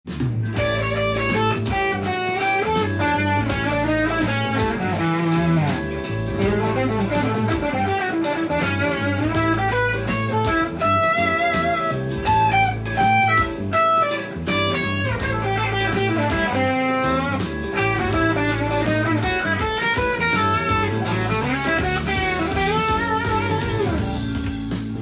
アドリブソロ実例tab譜
カラオケ音源にあわせて譜面通り弾いてみました